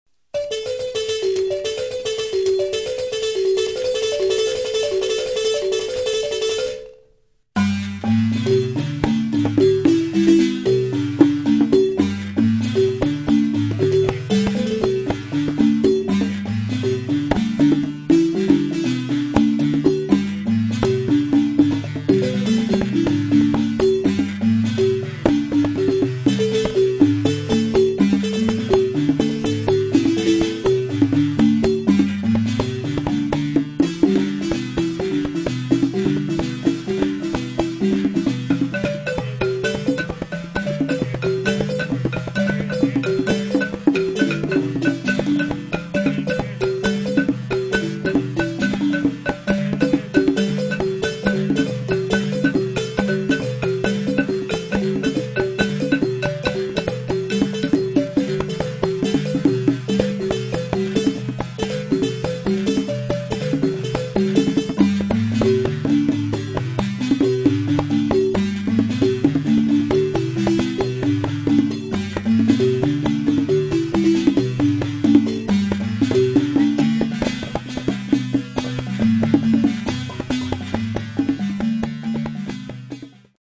the gyil
traditional gyil playing